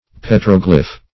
petroglyph \pet"ro*glyph`\ (p[e^]t"r[-o]*gl[i^]f`), n.